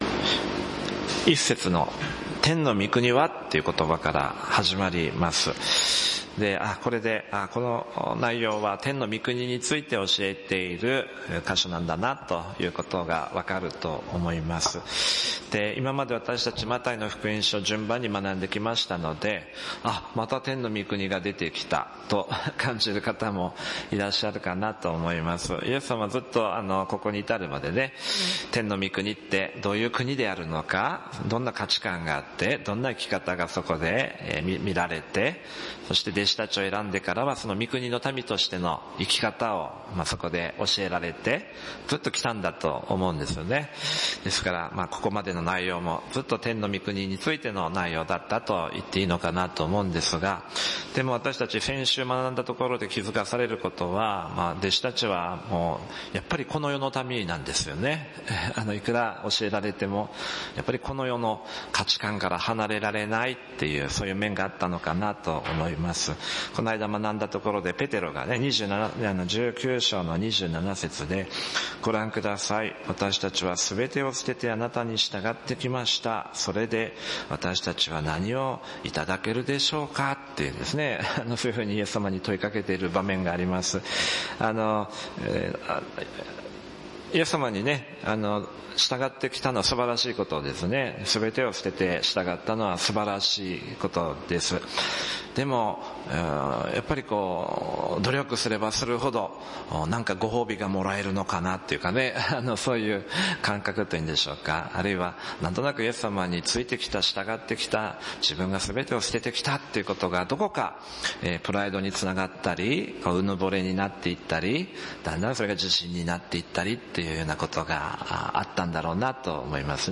毎聖日ごとの礼拝メッセージを書き起こし